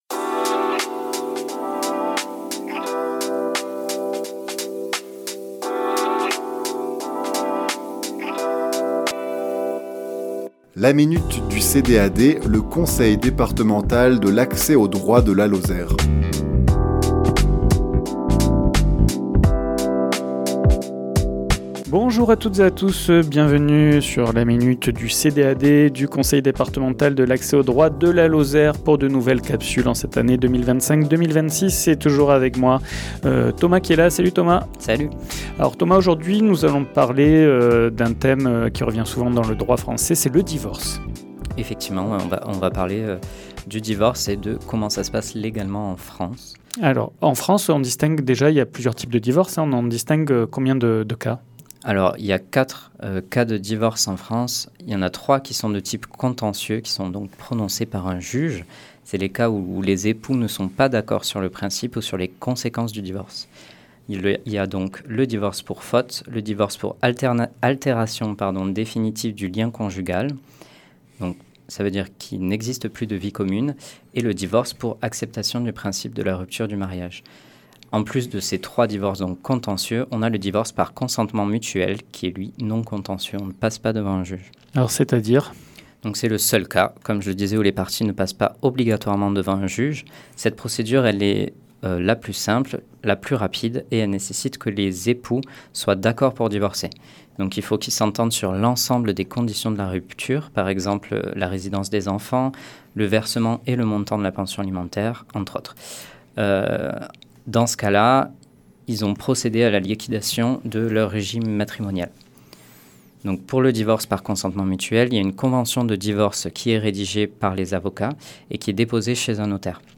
Chronique diffusée le lundi 29 septembre à 11h00 et 17h10